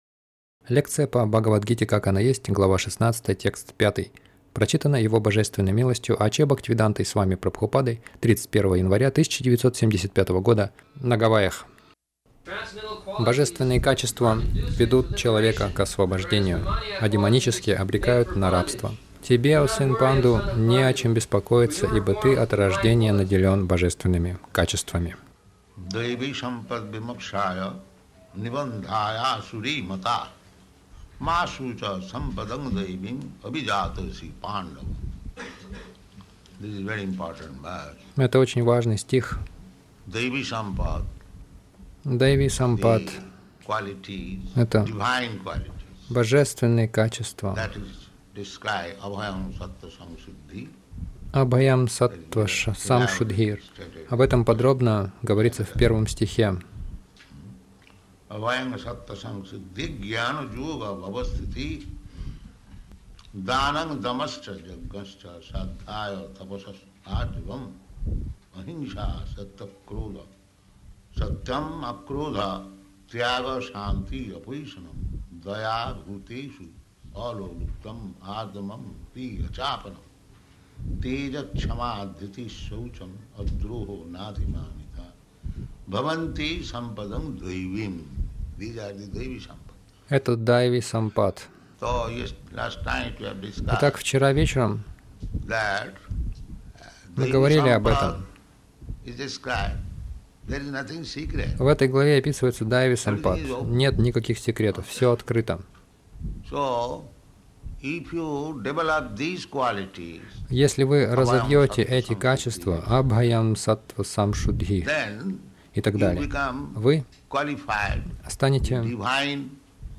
Милость Прабхупады Аудиолекции и книги 31.01.1975 Бхагавад Гита | Гавайи БГ 16.05 — Божественные качества освобождают Загрузка...